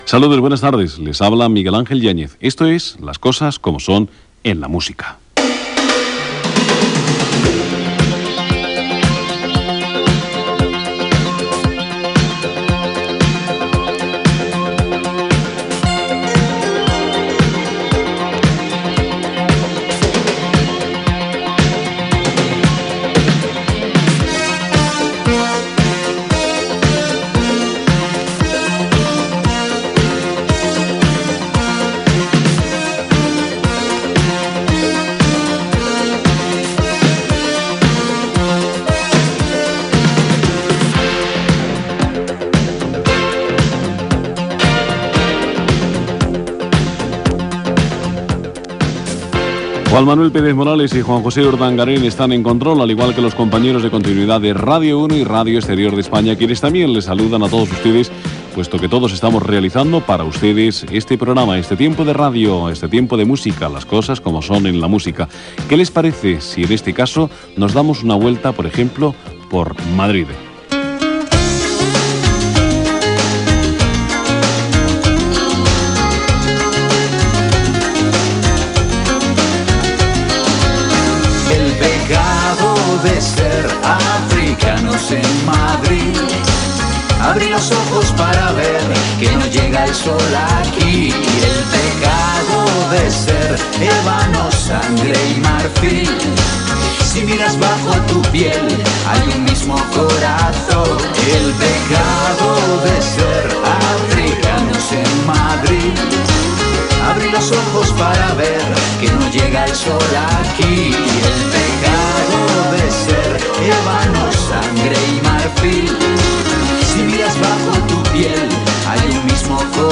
Presentació, equip, tema musical, comentari i tema musical
Musical